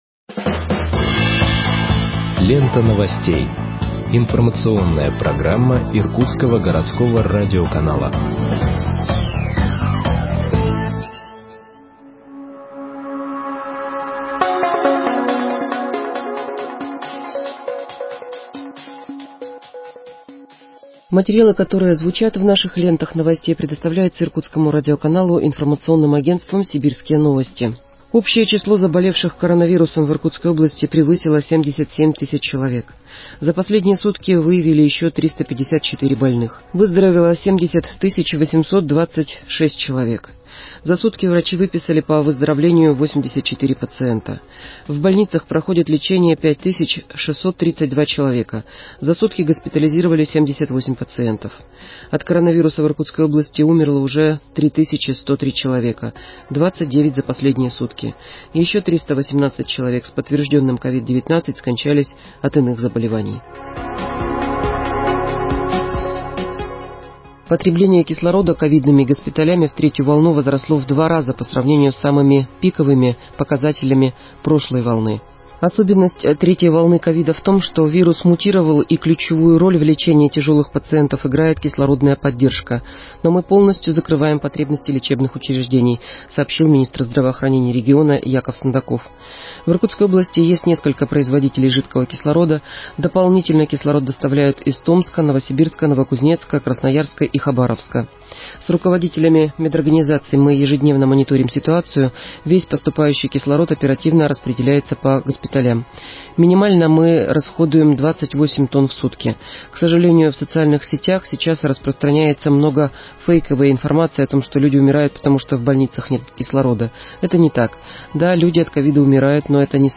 Выпуск новостей в подкастах газеты Иркутск от 09.07.2021 № 2